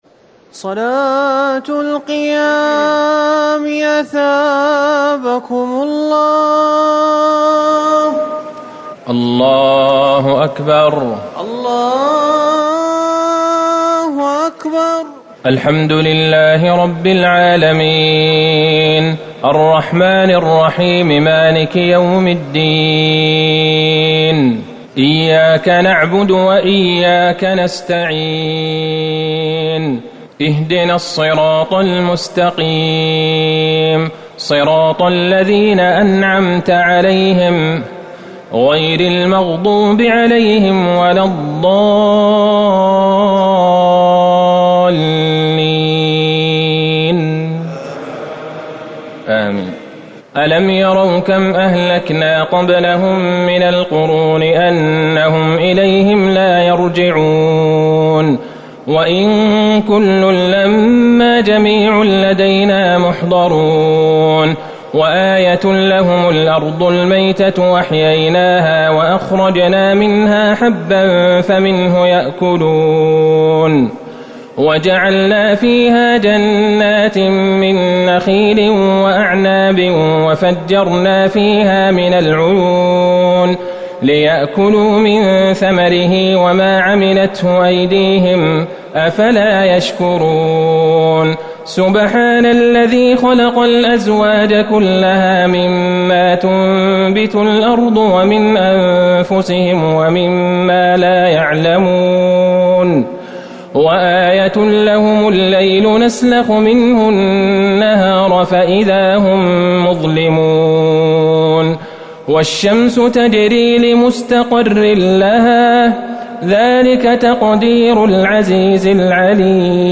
تراويح ٢٢ رمضان ١٤٤٠ من سورة يس ٣١ - الصافات ١٣٨ > تراويح الحرم النبوي عام 1440 🕌 > التراويح - تلاوات الحرمين